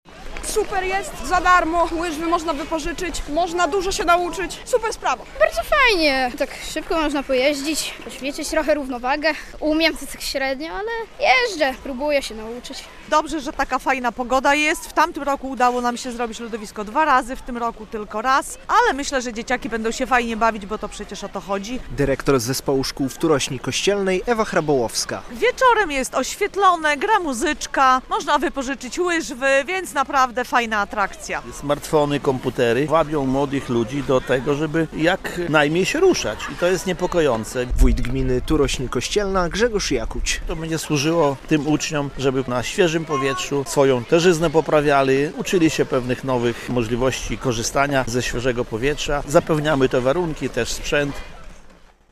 Lodowisko w Turośni Kościelnej - relacja
Smartfony, komputery wabią młodych ludzi do tego, żeby jak najmniej się ruszać i to jest niepokojące. To lodowisko będzie służyło uczniom i nie tylko do tego, żeby na świeżym powietrzu poprawiali swoją tężyznę fizyczną. Zapewniamy warunki, ale też sprzęt, można wypożyczać łyżwy - mówi wójt gminy Turośń Kościelna Grzegorz Jakuć.